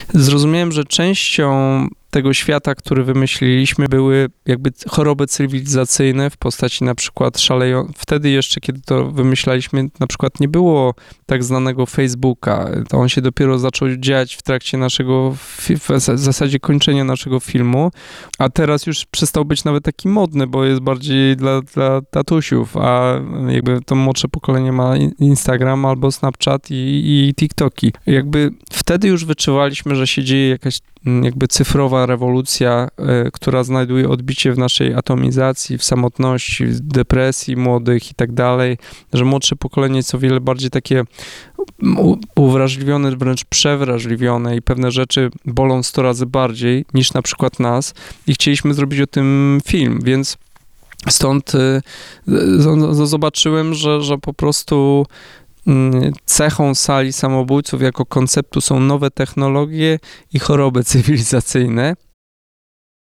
Podczas wywiadu z nami, Jan Komasa odpowiada na pytania dotyczące możliwego odbioru tejże produkcji w społeczeństwie amerykańskim, planów pozostania w Hollywood, stosunku do AI, czy wykorzystania obrazu René Magritte’a.